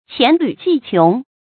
成語注音ㄑㄧㄢˊ ㄌㄩˊ ㄐㄧˋ ㄑㄩㄥˊ
成語拼音qián lǘ jì qióng
黔驢技窮發音
成語正音黔，不能讀作“jīn”。